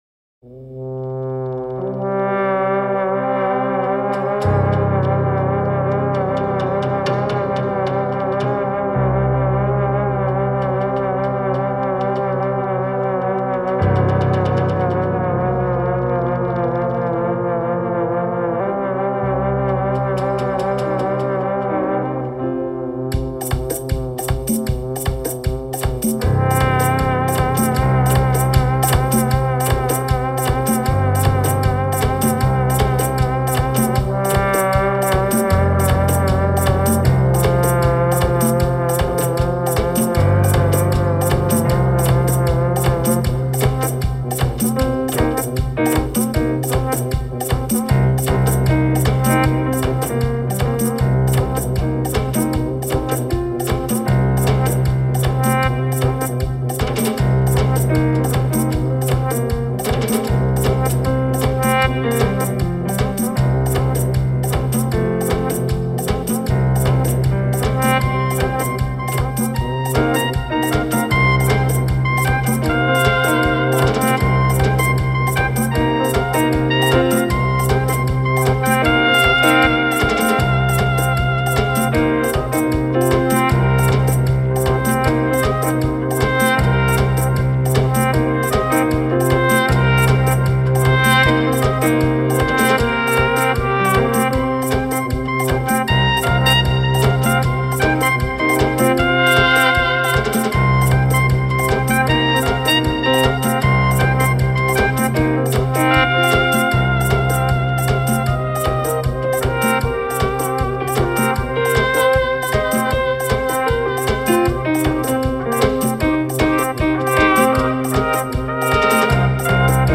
Tempo: 77 bpm / Datum: 03.03.2016